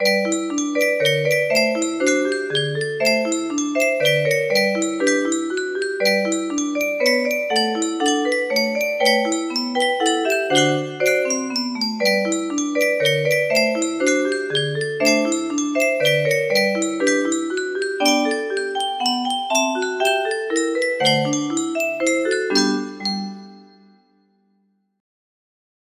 Anónimo - La Severiana music box melody
Aire nacional mexicano